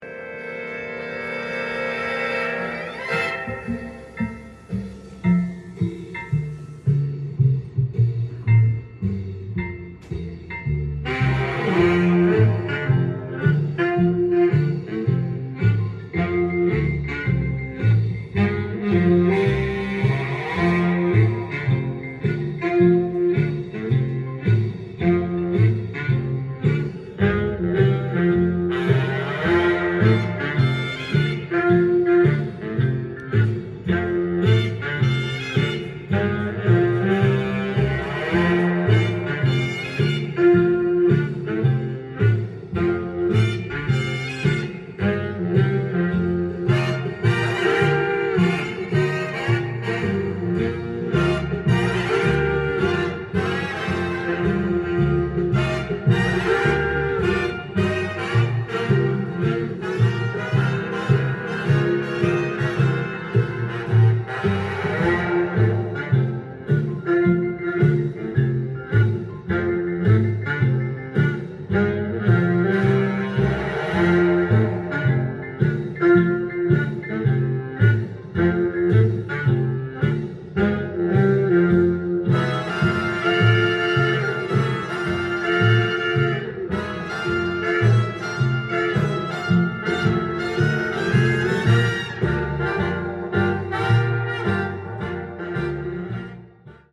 ジャンル：JAZZ-ALL
店頭で録音した音源の為、多少の外部音や音質の悪さはございますが、サンプルとしてご視聴ください。
軽快なリズムと、厚みのあるブラス・アンサンブルが心地よく、針を落とした瞬間に50年代の華やかなムードが広がります。